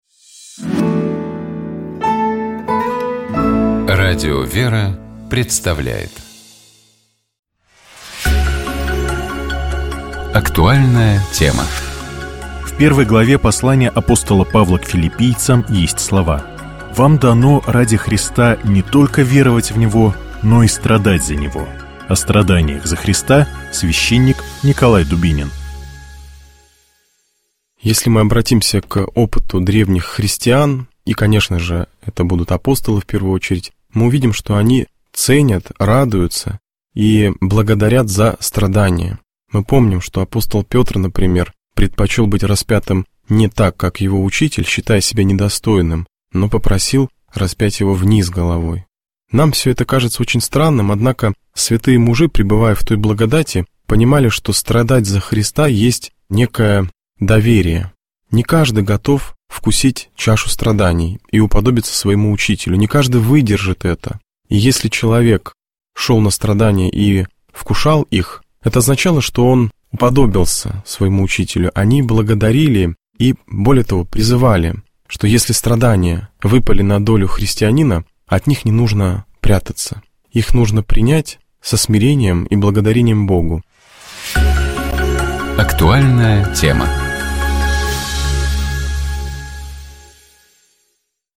О страданиях за Христа — священник